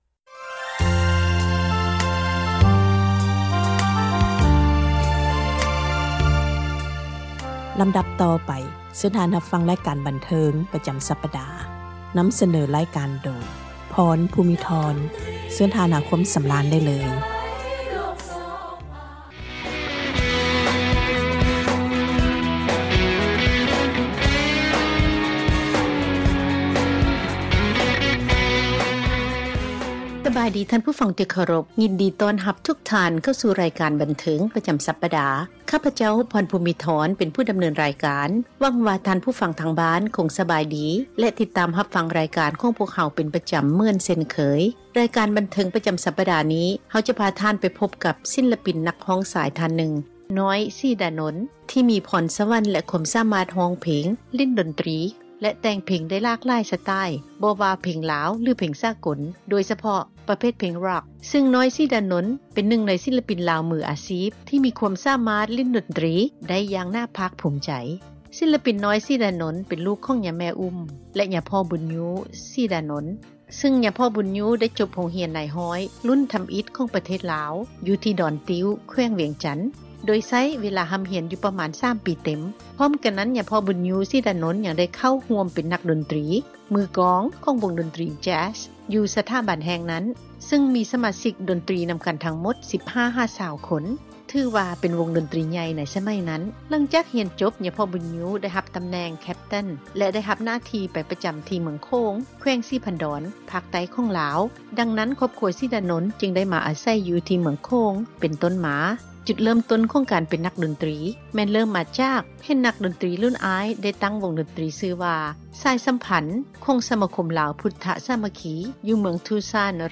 ການສັມ ພາດ